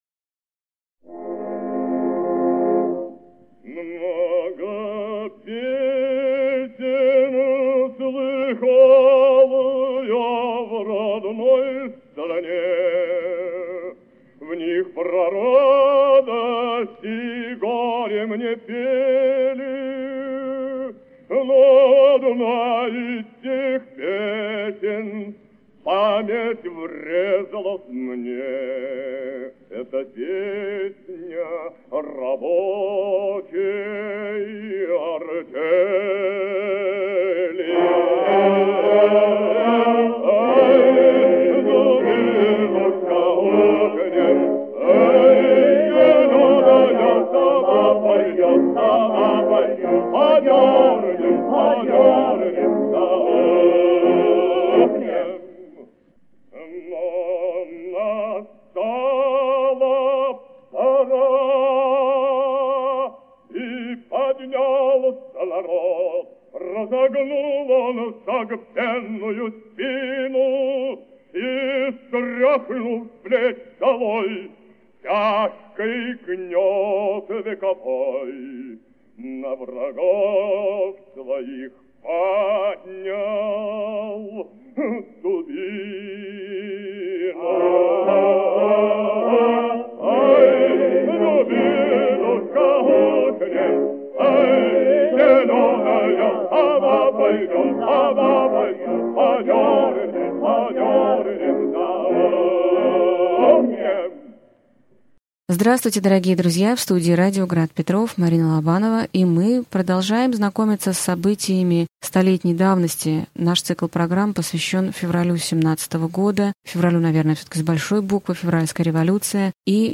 Аудиокнига Февральская революция и отречение Николая II. Лекция 26 | Библиотека аудиокниг